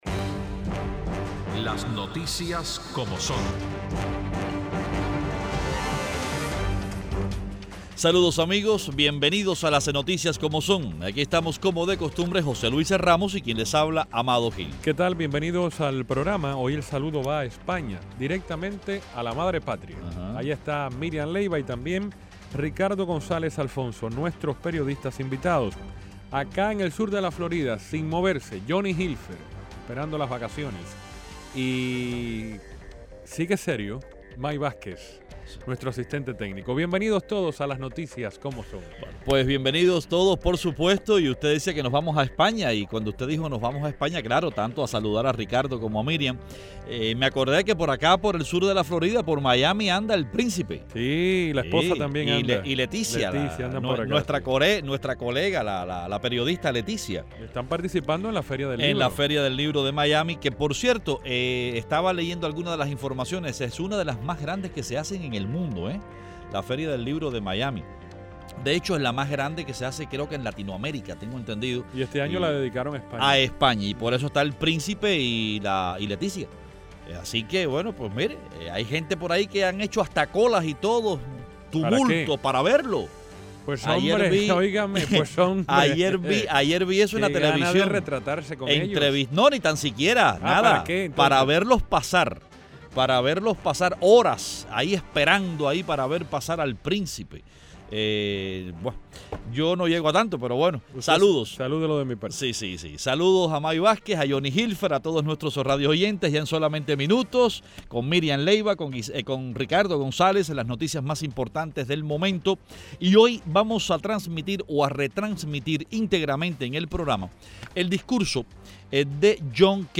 Los periodistas cubanos